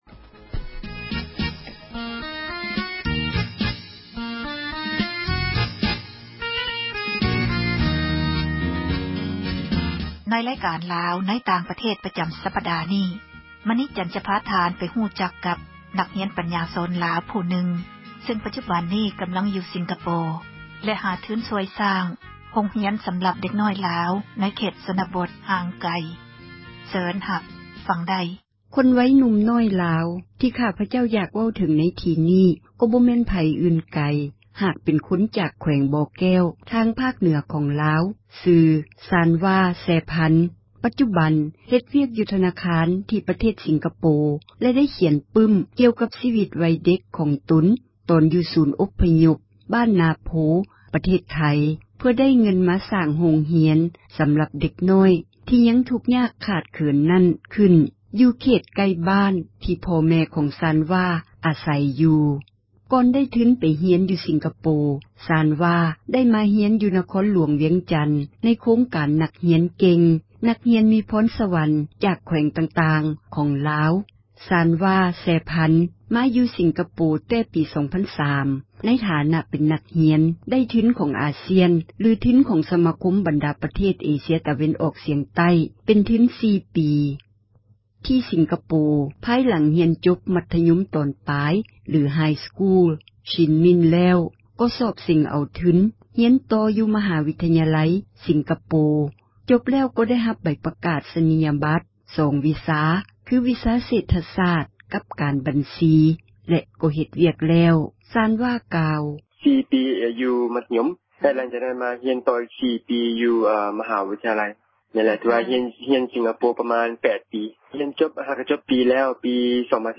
ສັມພາດອະດີດ ລາວອົພຍົບ